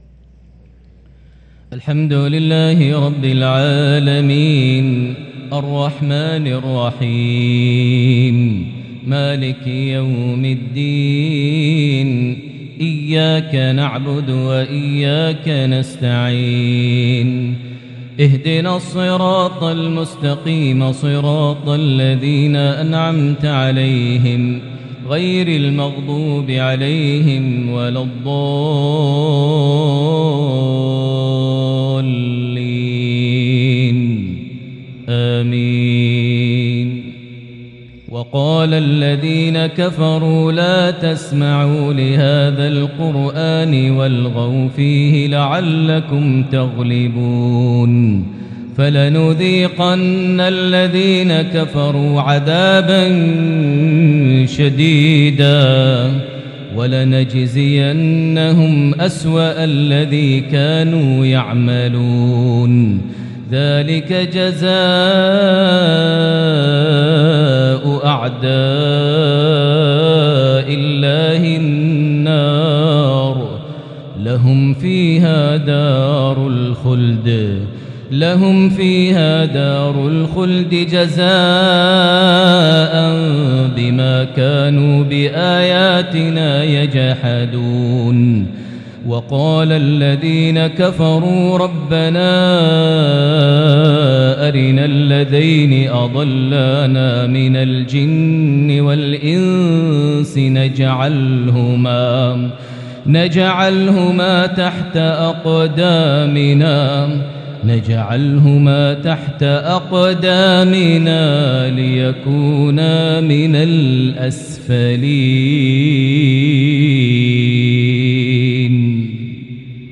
maghrib 3-3-2022 prayer from Surah Fussilat 26-35 > 1443 H > Prayers - Maher Almuaiqly Recitations